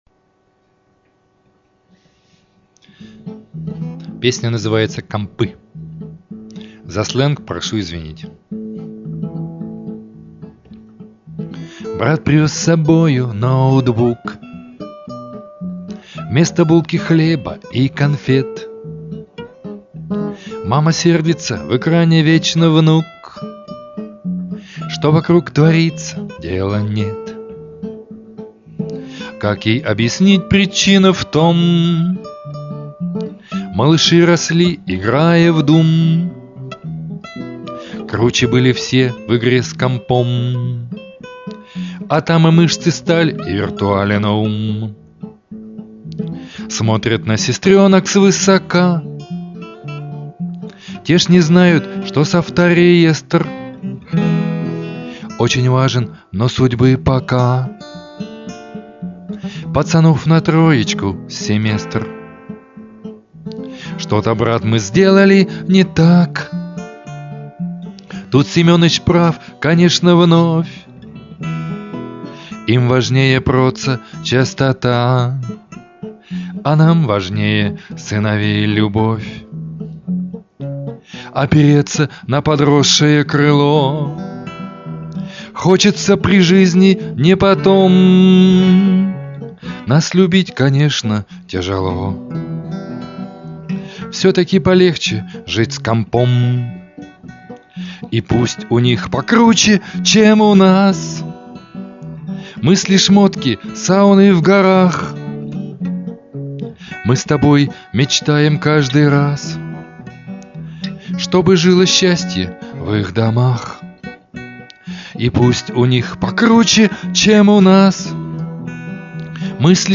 Авторская песня
Скачать авторское исполнение